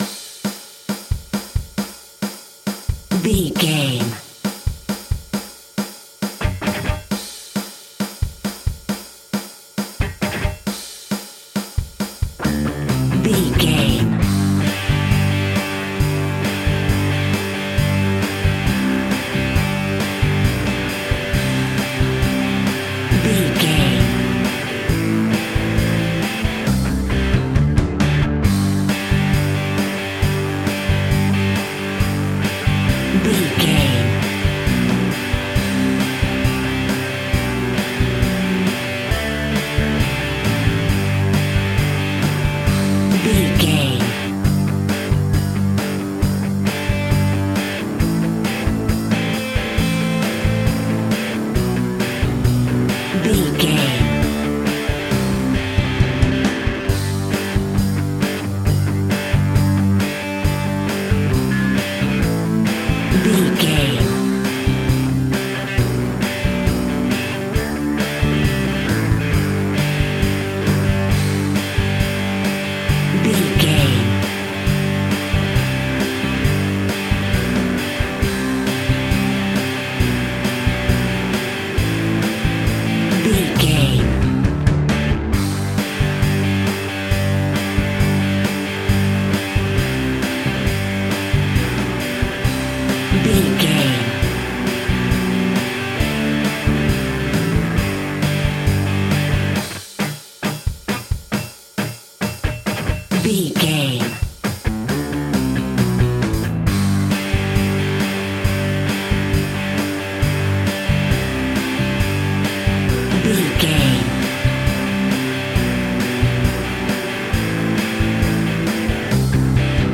Full on Rock.
Epic / Action
Fast paced
Ionian/Major
heavy rock
distortion
hard rock
Instrumental rock
drums
bass guitar
electric guitar
piano
hammond organ